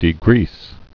(dē-grēs, -grēz)